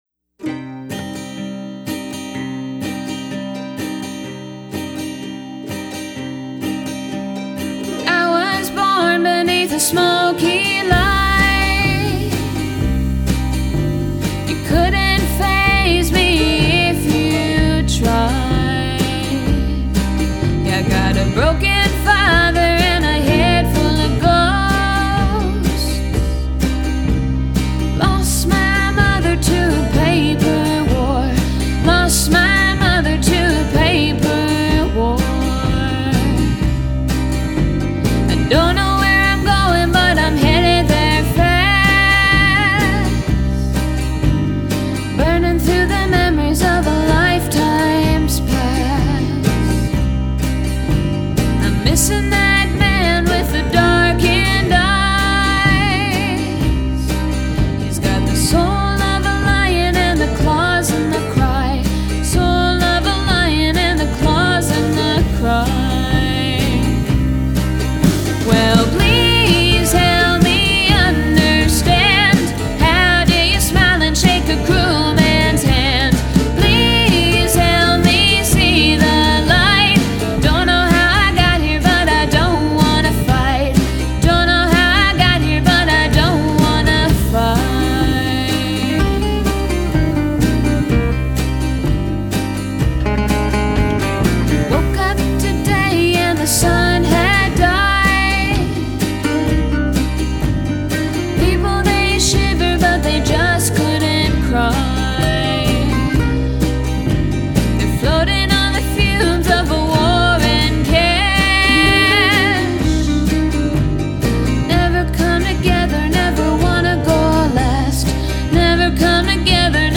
Recorded at The Lincoln County Social Club.
Vocals, acoustic Guitar
drums, tambourine, shaky skull.